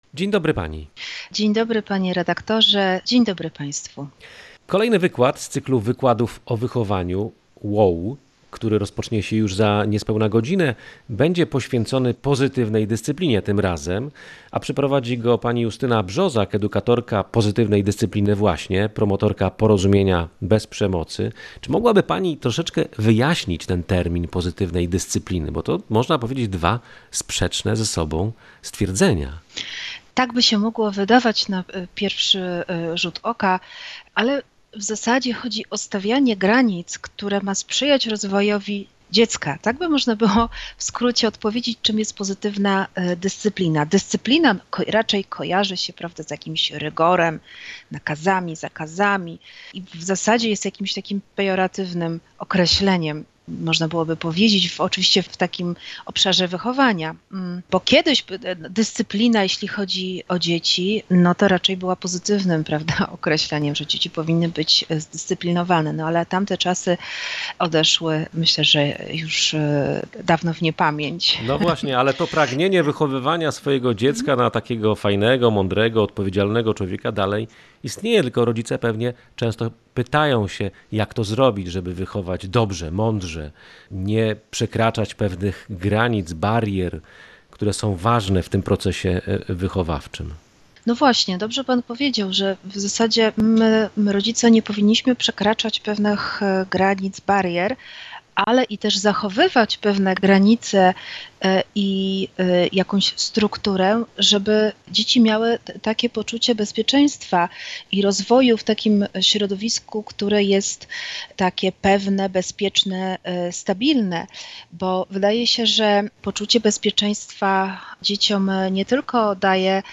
Pozytywna dyscyplina, czyli rozmowa o wartości dialogu w wychowaniu dzieci.